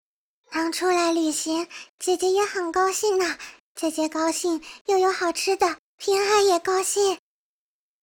贡献 ） 协议：Copyright，人物： 碧蓝航线:平海语音 2021年2月4日